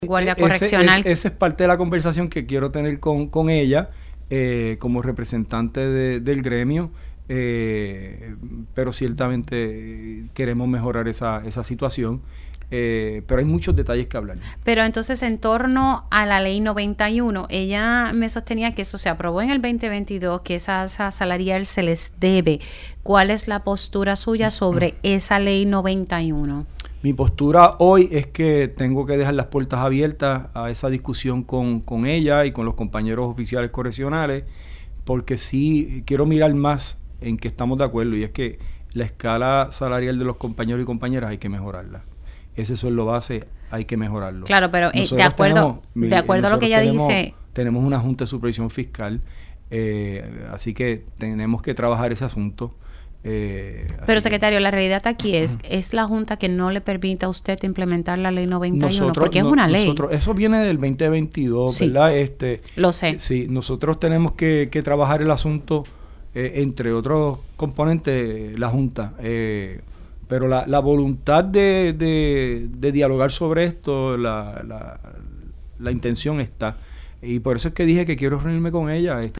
El secretario de Corrección, Francisco Quiñones reveló en Pegaos en la Mañana que el Departamento de Corrección y Rehabilitación (DCR) no cuenta con suficientes fondos para poder otorgar el aumento retroactivo- así como lo estipula la ley 91 del 2022 – a los oficiales correccionales.